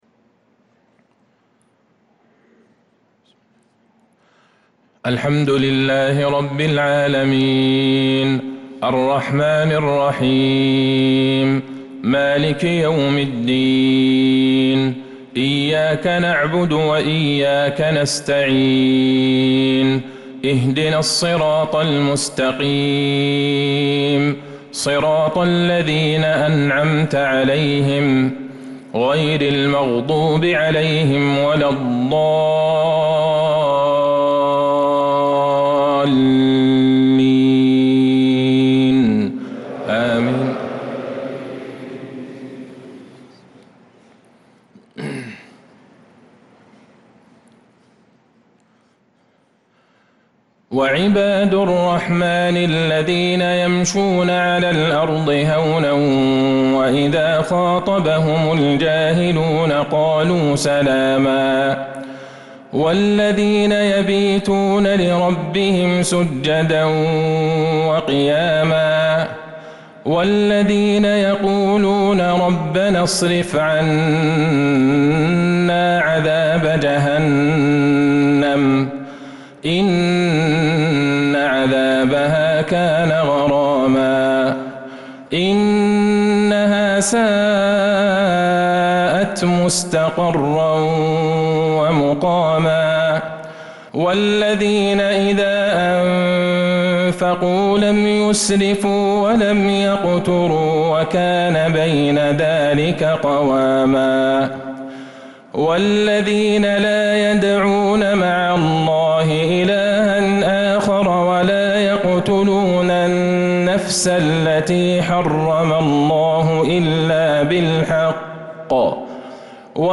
عشاء الجمعة ١٣ ربيع الأول ١٤٤٧ من سورة الفرقان 63-77 | Isha prayer from Surah Al-furqan 5-9-2025 > 1447 🕌 > الفروض - تلاوات الحرمين